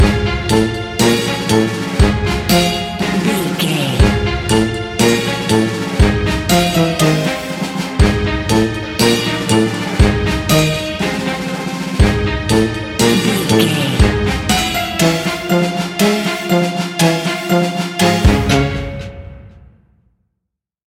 Aeolian/Minor
C#
Slow
scary
ominous
eerie
playful
bouncy
percussion
brass
synthesiser
strings
spooky
horror music